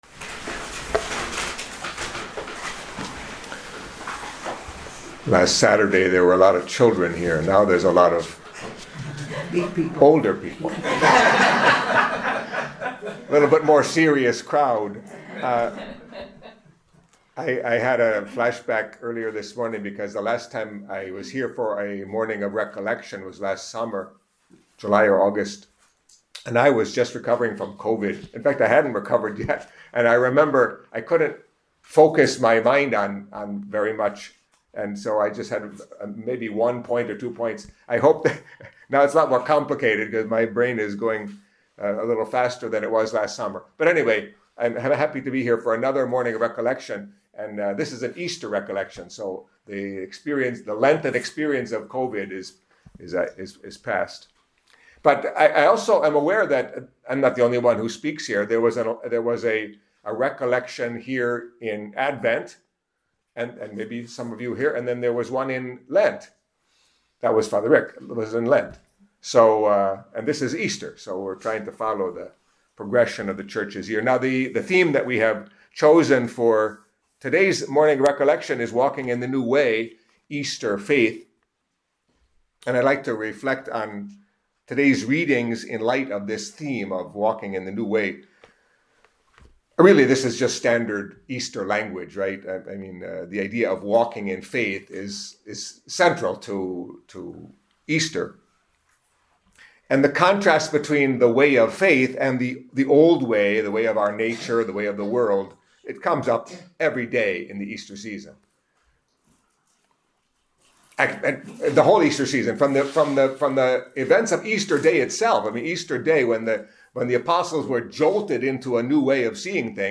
Catholic Mass homily for Saturday of the Fourth Week of Easter